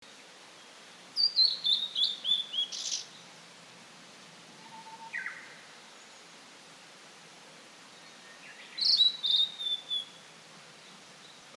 ooruri.mp3